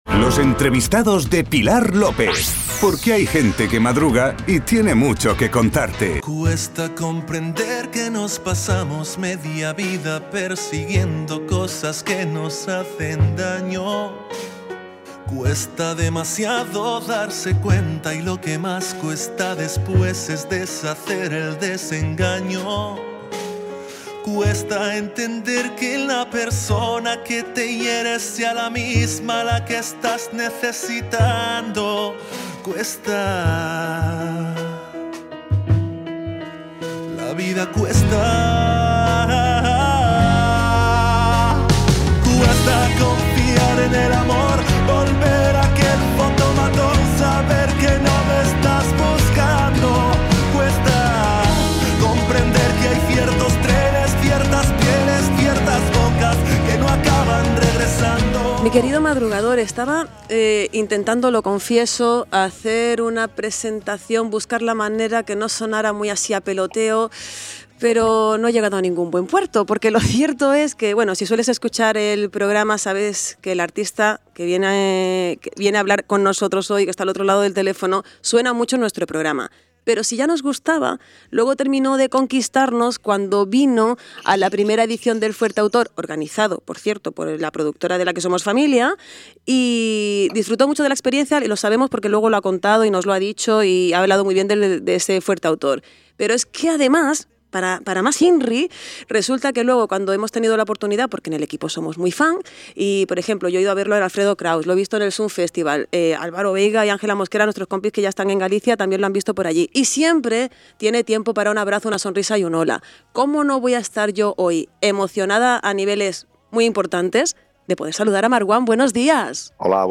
Entrevista en el programa Suena Bien al cantautor Marwán - Radio Insular
Entrevista-en-el-programa-Suena-Bien-al-cantautor-Marwan.mp3